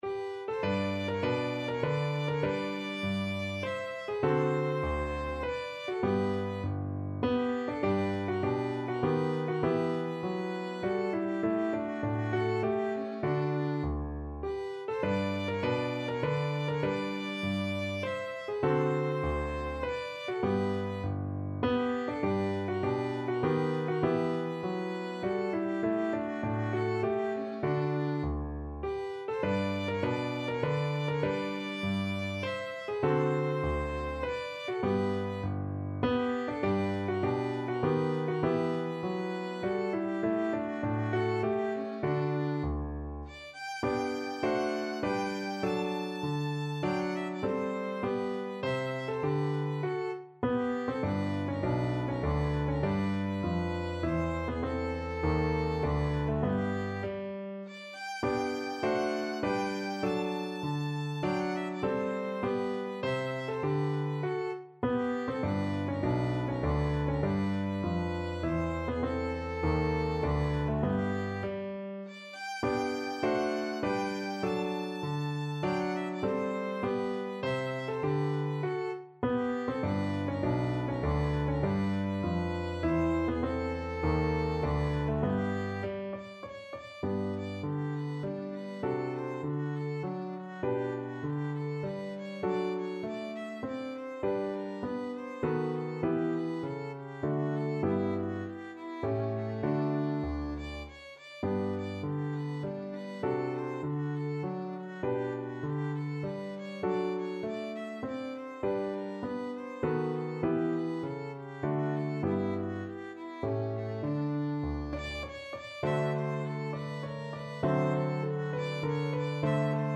ViolinPiano
3/4 (View more 3/4 Music)
Violin  (View more Easy Violin Music)
Classical (View more Classical Violin Music)